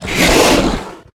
attack_hit_3.ogg